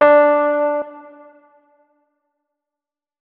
electric_piano
notes-38.ogg